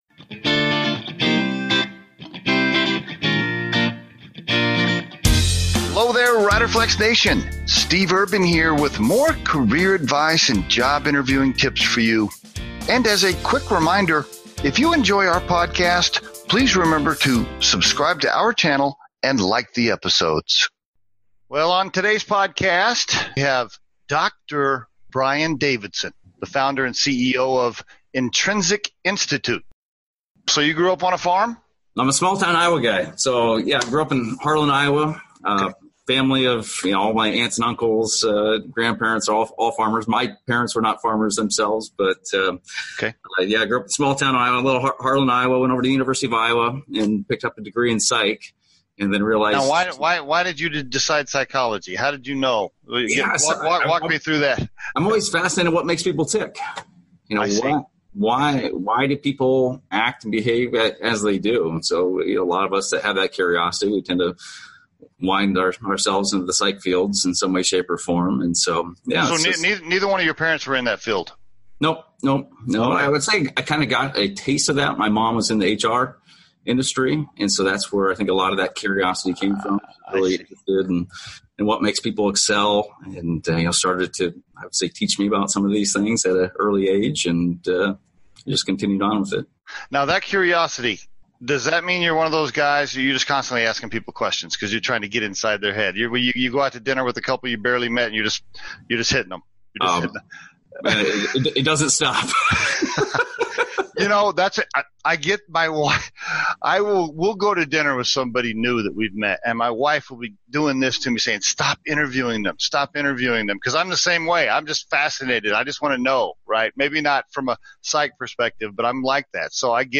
We give Career Advice and Job Interviewing Tips on this podcast. In addition, we interview the most successful entrepreneurs, CEO's, and business leaders.